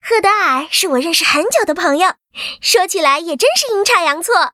文件 文件历史 文件用途 全域文件用途 Fifi_amb_05.ogg （Ogg Vorbis声音文件，长度4.5秒，101 kbps，文件大小：56 KB） 源地址:游戏语音 文件历史 点击某个日期/时间查看对应时刻的文件。